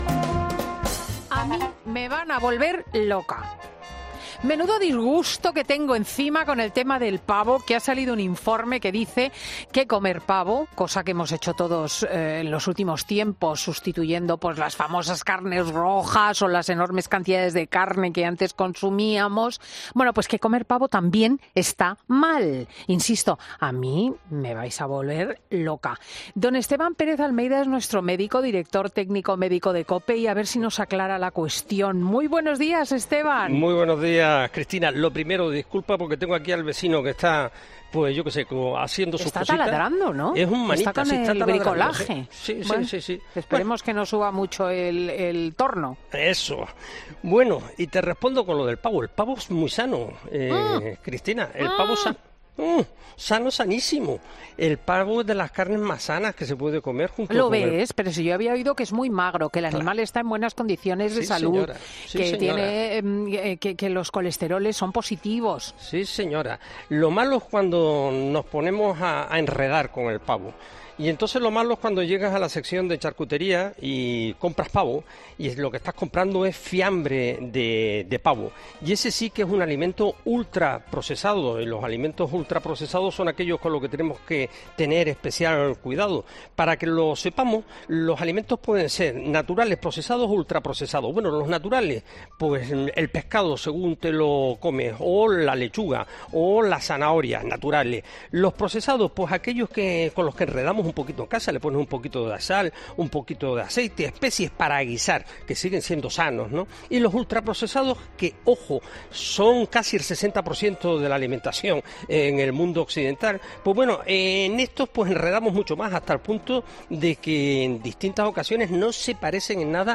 Entonces, el entrevistado ha explicado cómo se puede hacer esta pechuga en casa: "Vas a la carnicería y pides un kilo y cuarto de pechuga de pavo. Luego, en casa, coges sal para hornear y especias. Entonces, pones especias a la pechuga, la atas, la envuelves como si estuvieses haciendo un pescado a la sal. La envuelves en sal y la metes al horno durante una hora más o menos. La sacas del horno y lo tienes, mucho más barato y sano".